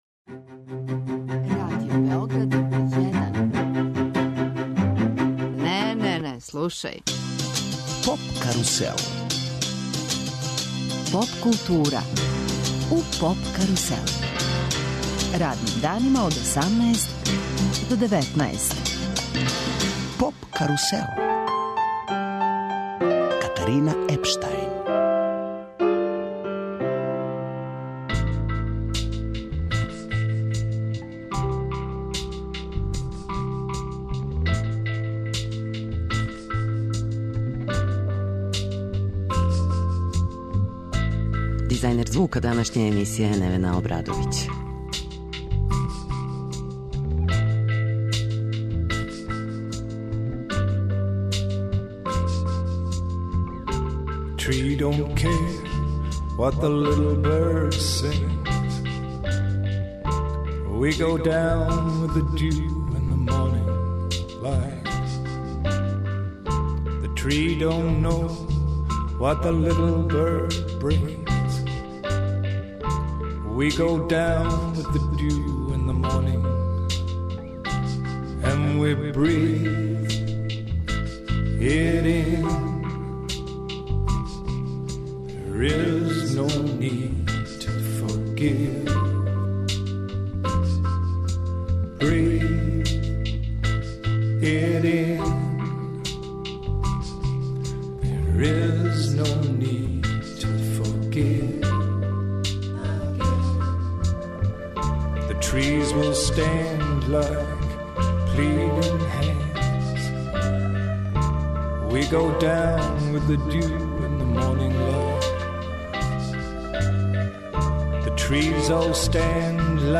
Гошћа емисије је Амира Медуњанин, светски позната певачица босанске севдалинке и традиционалне музике са Балкана.